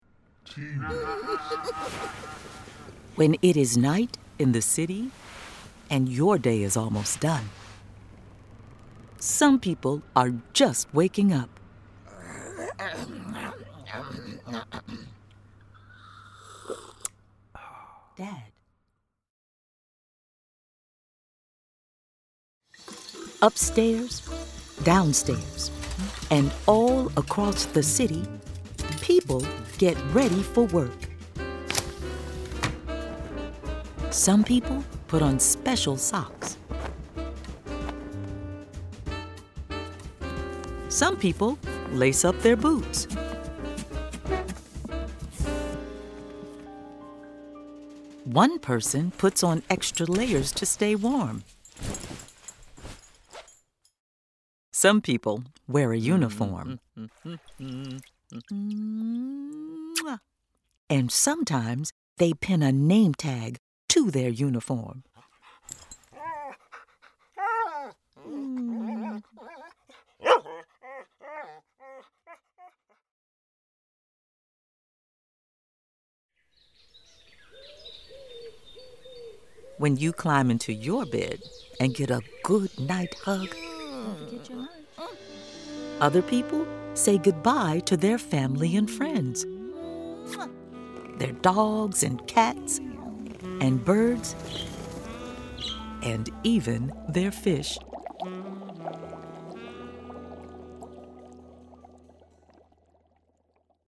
Narrator:
Its central character is a child of color who is going to bed as nighttime activity is carried out by a diverse group of adults with a variety of occupations...Background sound effects--car engines, sirens, a dog yapping--underscore the bustle." AudioFile Magazine